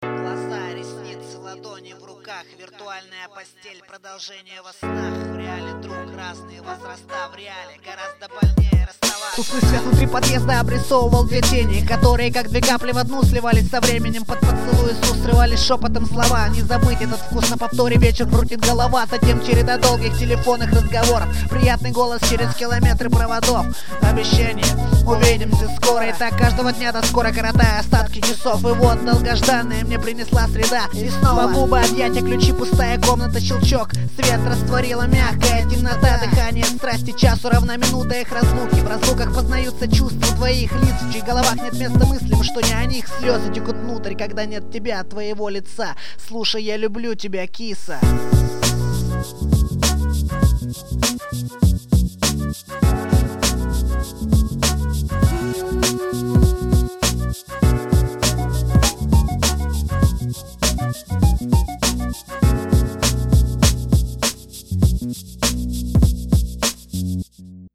2007 Рэп